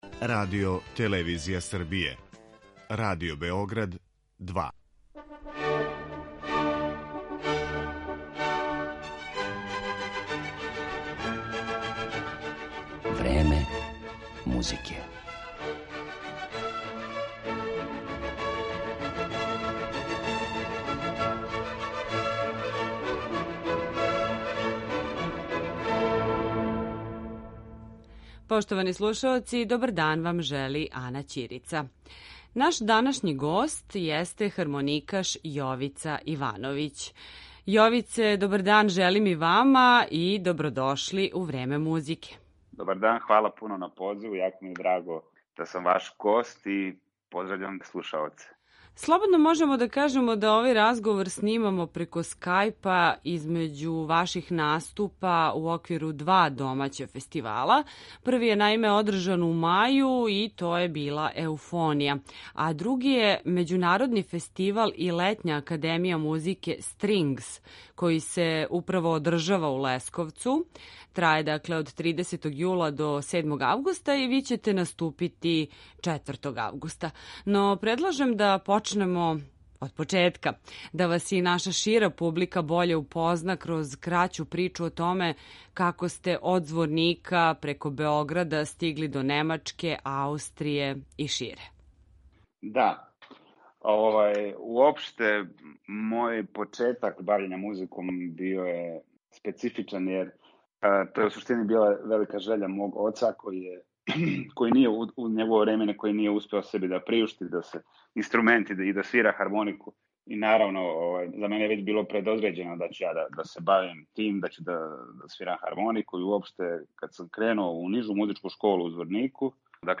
Хармоникаш